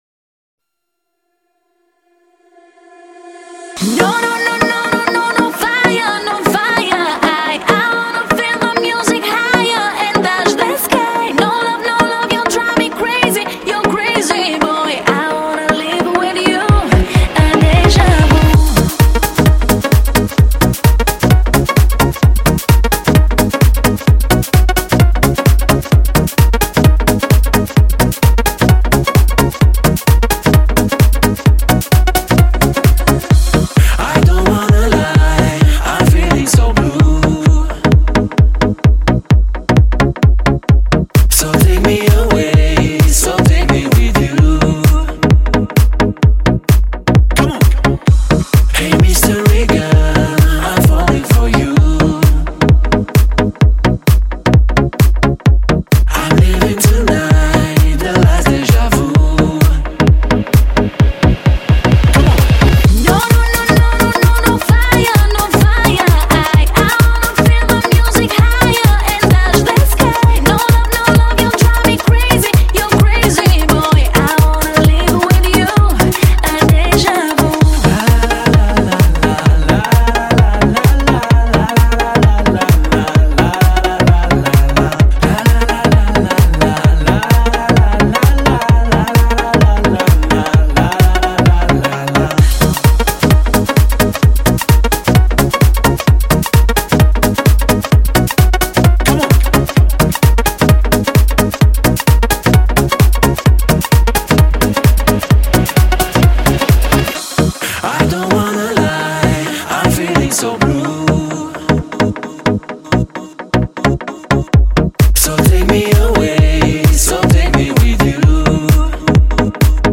Жанр:Club/Dance